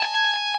guitar_023.ogg